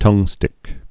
(tŭngstĭk)